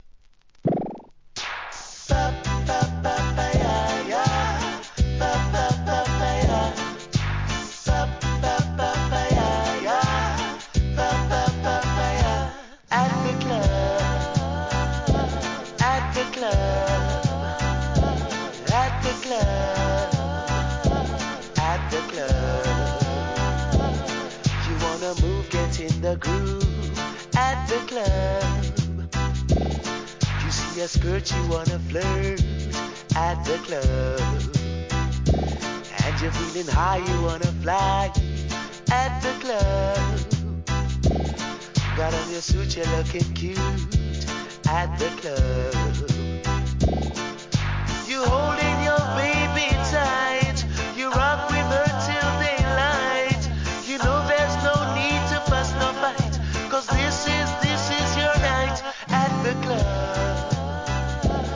REGGAE
ムーディーなLOVERS REGGAEを集めた好コンピ第7弾!!